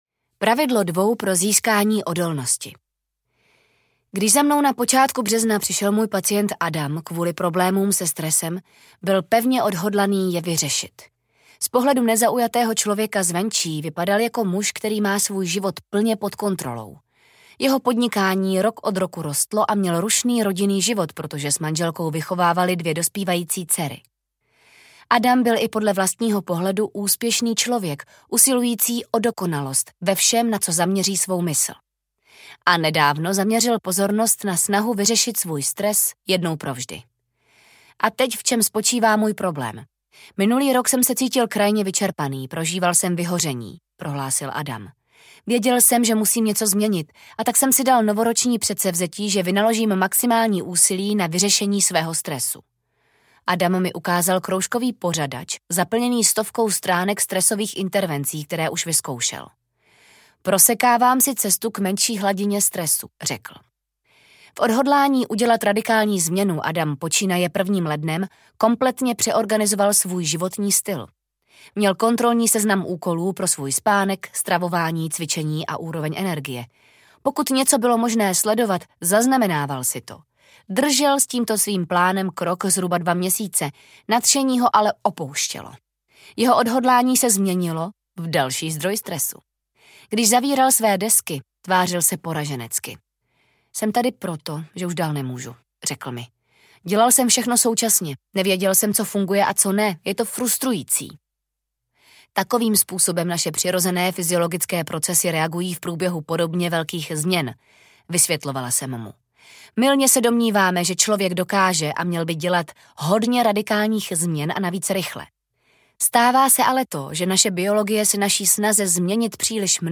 5 resetů audiokniha
Ukázka z knihy